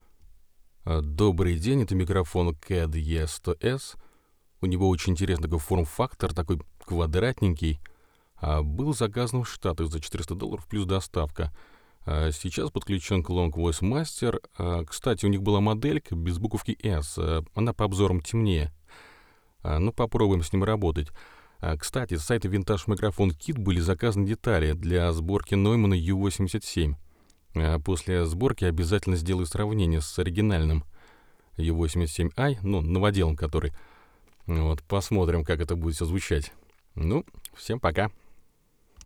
На покупку вдохновился после обзорах на буржуйских сайтах и ютубах :) Производитель хвастается низким уровнем шума 3.7 db.
Необработанный файл с зума h6 во вложении.